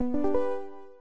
screen_share_started-DH3qxml5.mp3